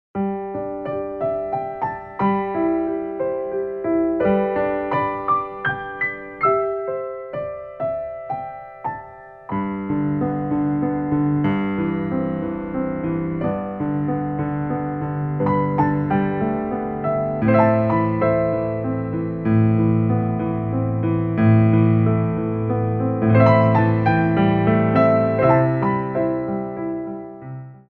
Grands Battements
4/4 (16x8)